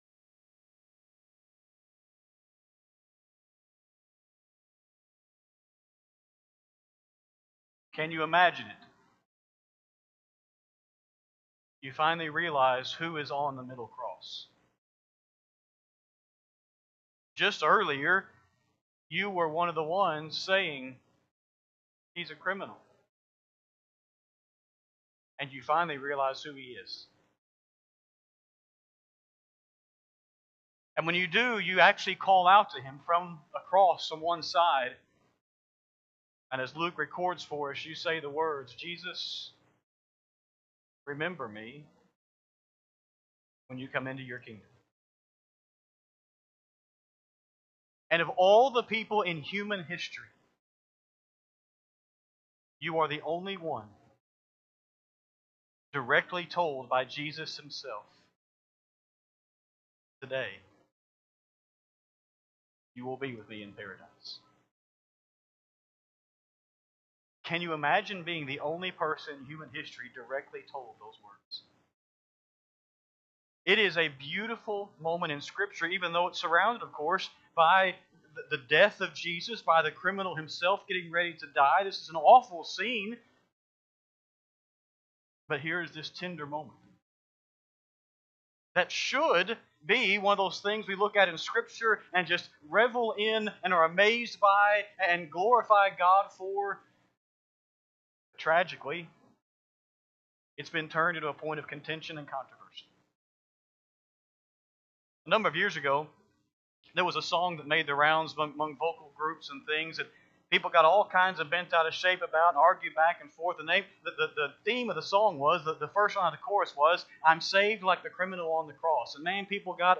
Sunday-PM-Sermon-11-2-25-Audio.mp3